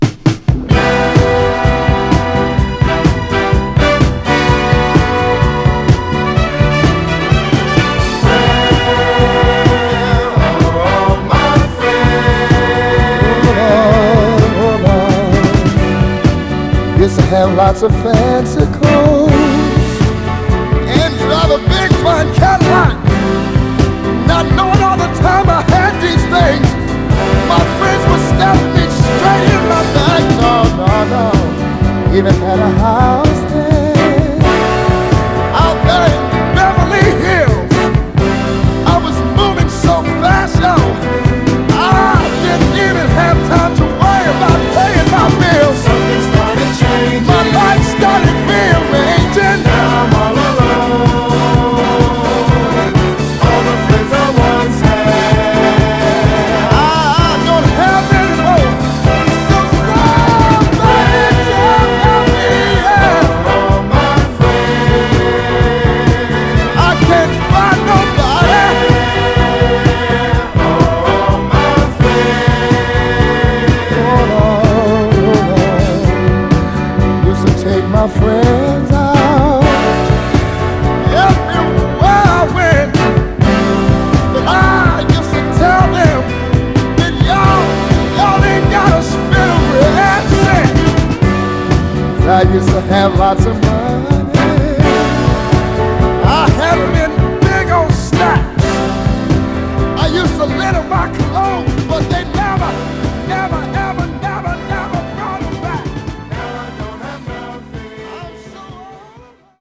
※試聴音源は実際にお送りする商品から録音したものです※